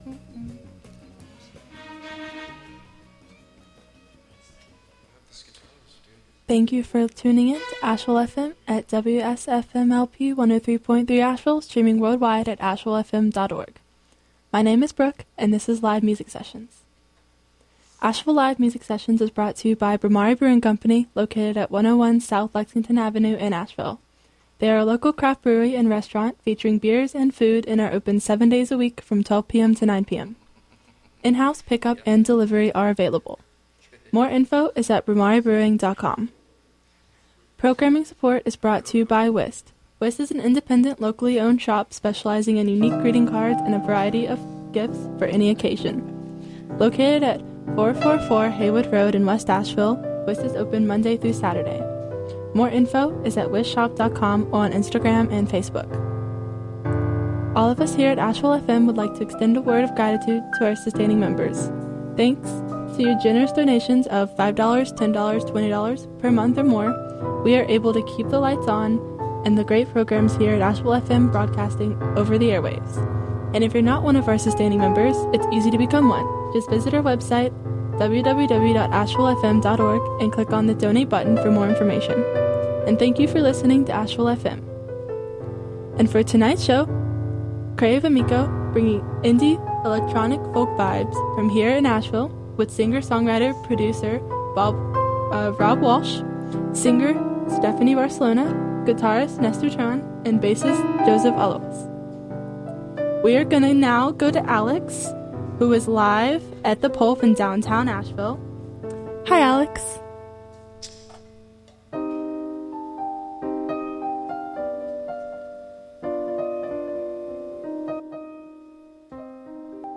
Recorded during safe harbor – Explicit language warning
guitarist
bassist